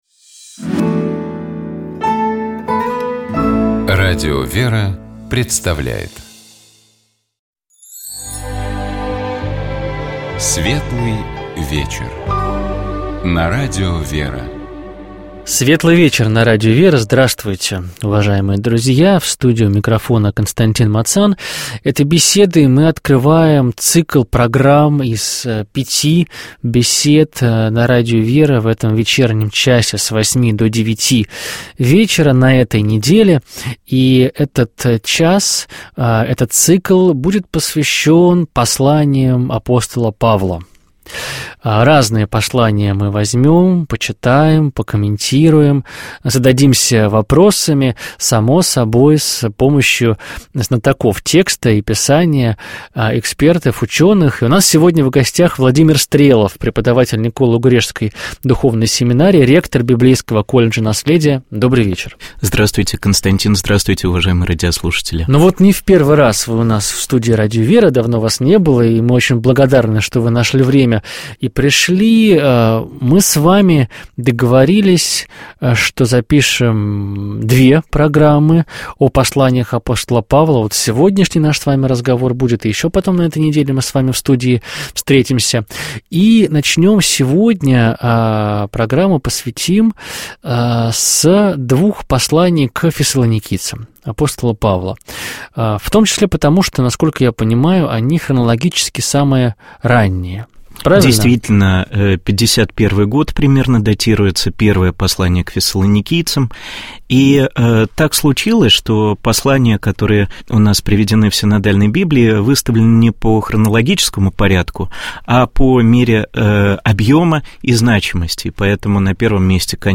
Радио ВЕРА